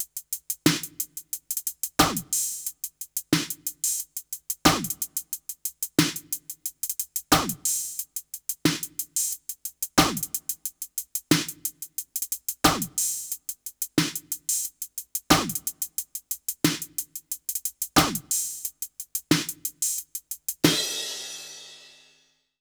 British REGGAE Loop 091BPM (NO KICK).wav